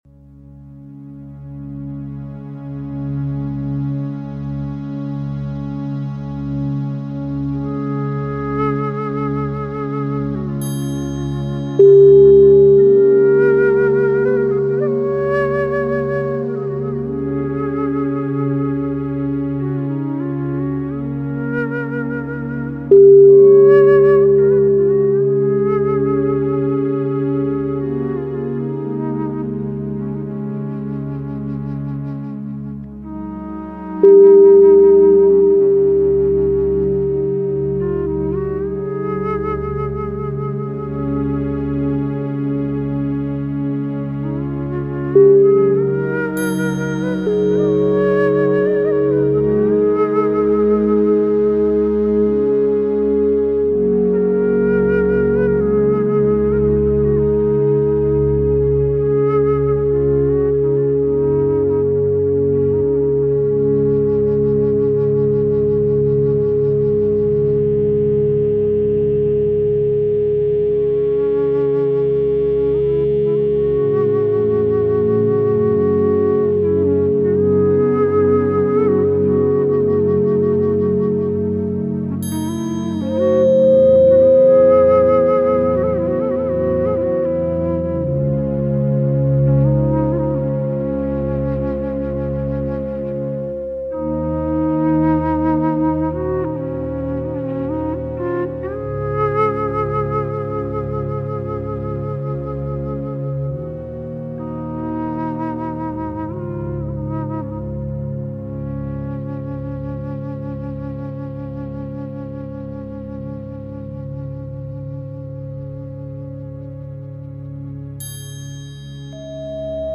4 Minute Chakra Re-Alignment | Healing Frequency Sounds for Balance Experience a powerful 4-minute chakra re-alignment journey through the resonating frequencies of each chakra. Allow the gentle, healing tones to restore your energy flow and balance your mind, body, and spirit.